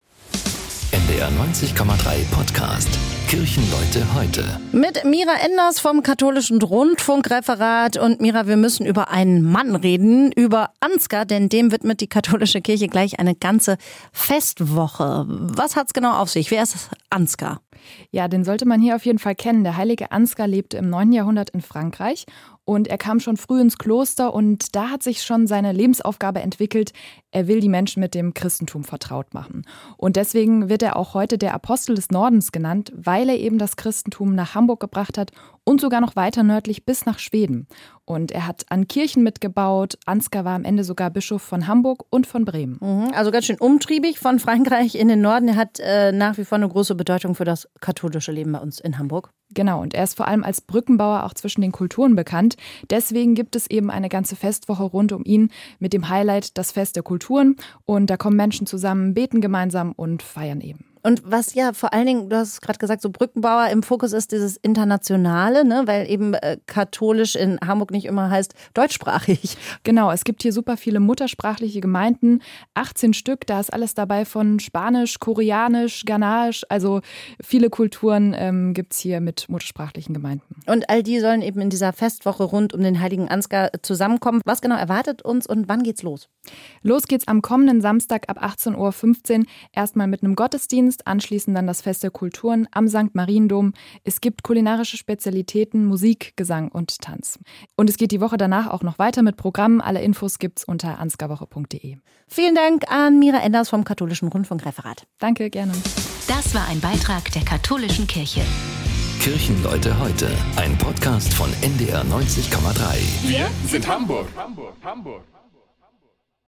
Hamburger Pastorinnen und Pastoren und andere Kirchenleute erzählen